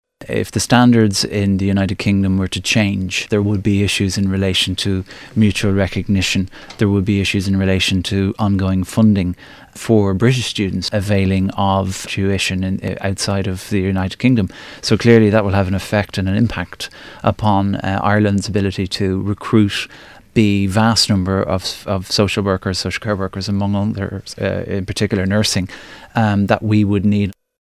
Chairman of the Children and Youth Affairs Committee – Alan Farrell – says the issue of nursing and social work qualifications is of particular concern: